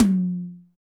Royality free tom drum tuned to the F note. Loudest frequency: 2220Hz
• Large Room Tom One Shot F Key 16.wav
large-room-tom-one-shot-f-key-16-PuJ.wav